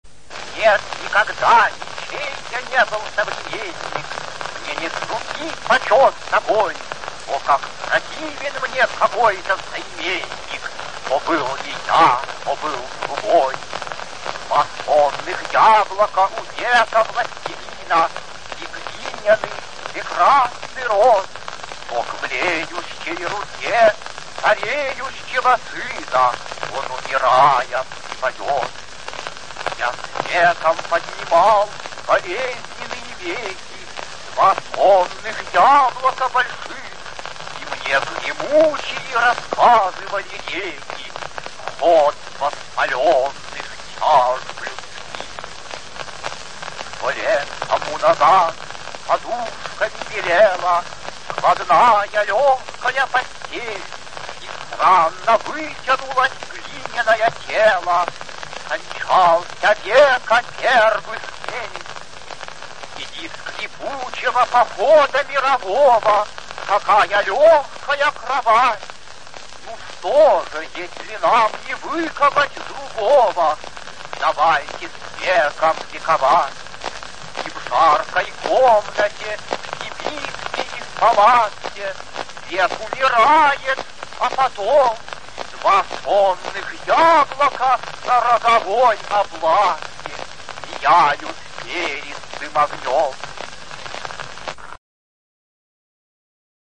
osip-mandelshtam-net-nikogda-nichej-ya-ne-byl-sovremennik-chitaet-avtor-muzej-rubleva